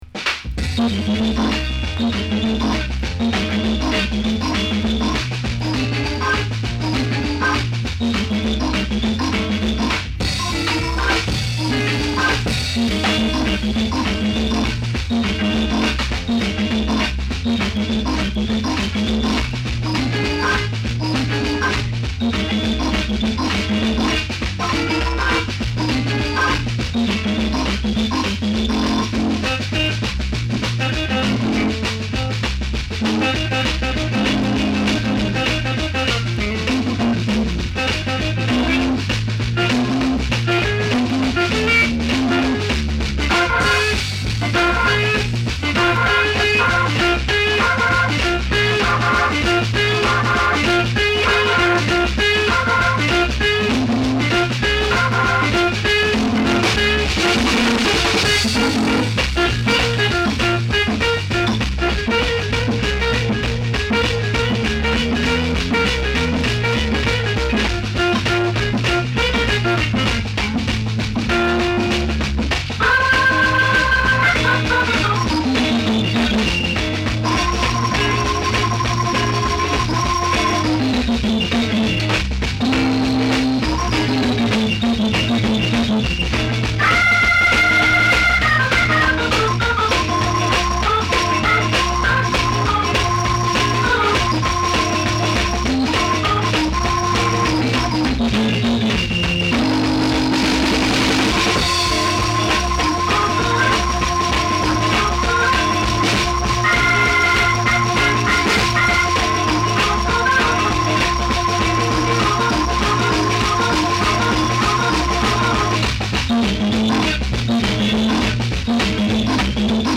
St. Louis, late 1959